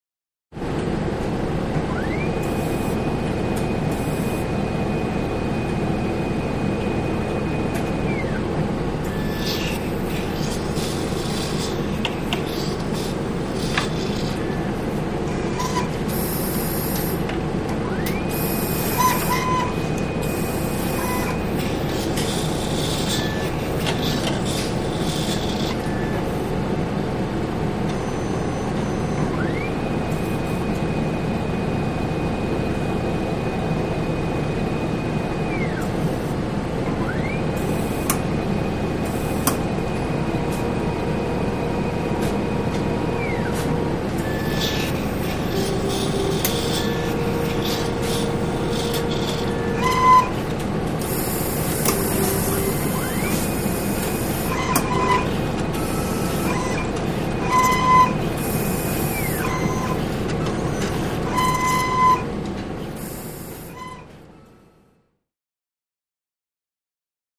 Lab Ambience; Test Machinery 2; Large Room; Fan / Motor, Air Releases, Servos, Beeps, Computer Keyboard Entry / Printing; Medium Perspective. Hospital, Lab.